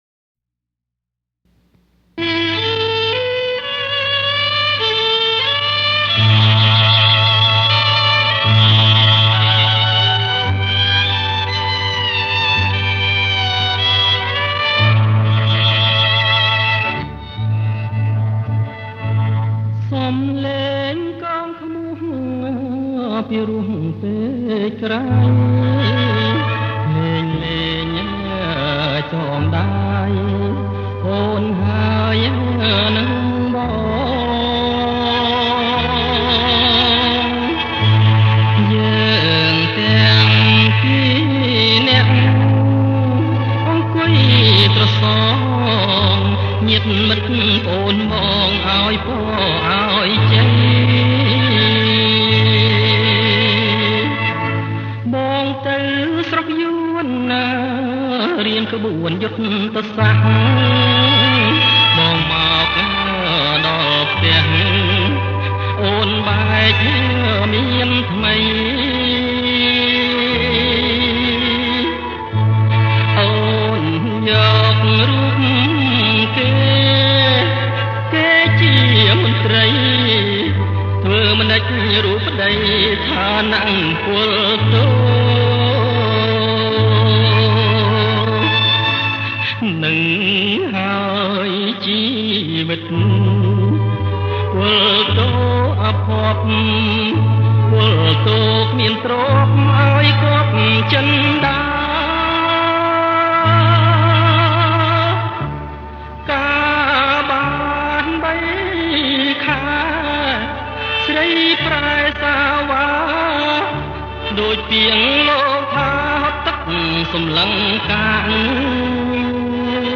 • ប្រគំជាចង្វាក់ Bolero Sentimental